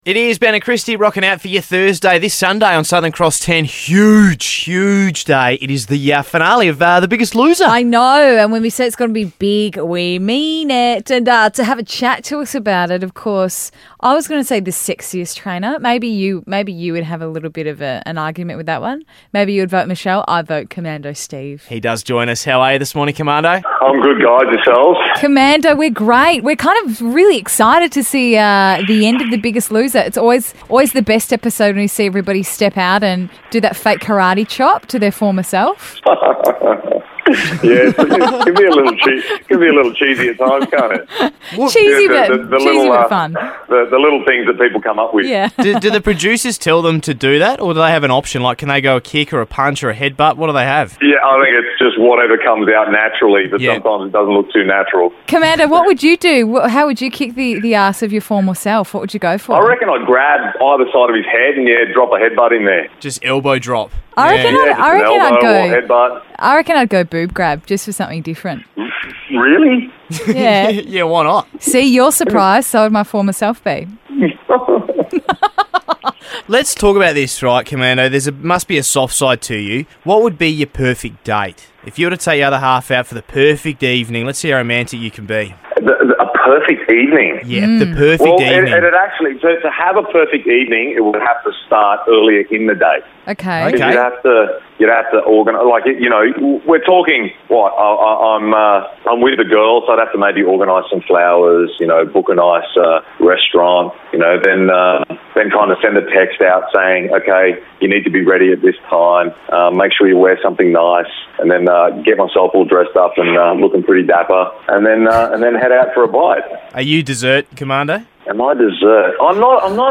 most AWKWARD interview EVER